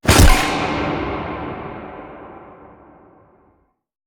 Efecto especial de golpe fuerte
golpe
Sonidos: Especiales